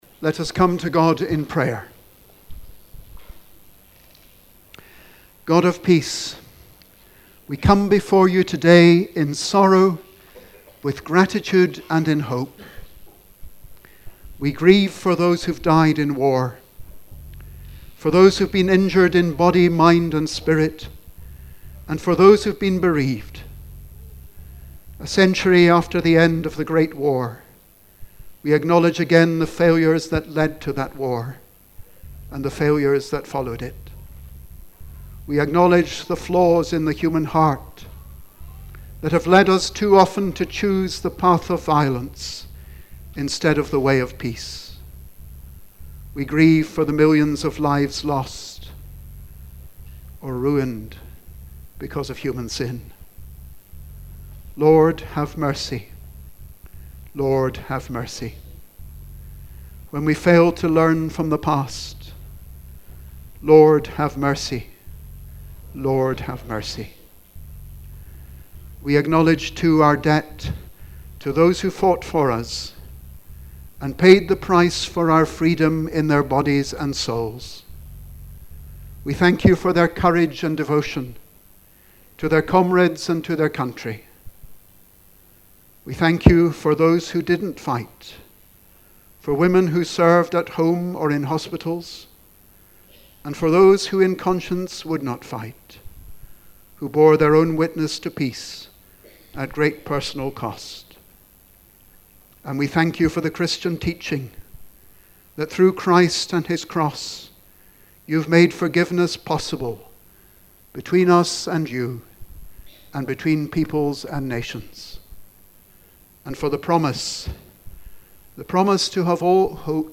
prayerofconfession.mp3